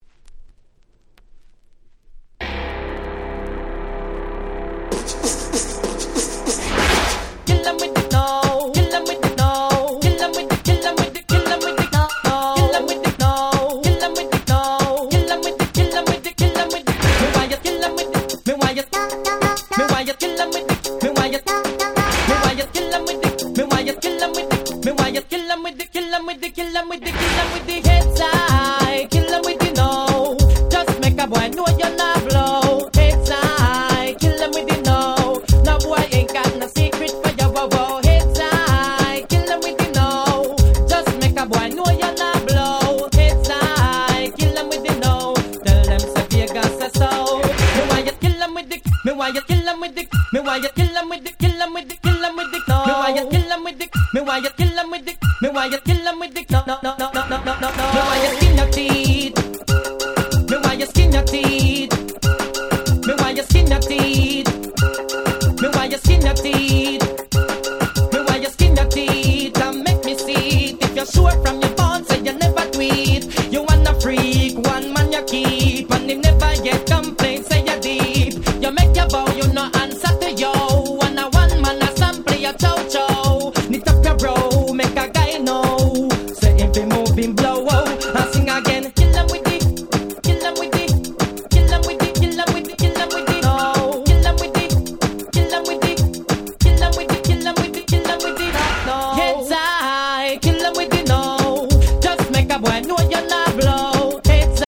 Nice Dancehall Reggaeコンピレーション！！